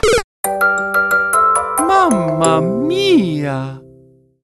A Mario variant of an arrangement of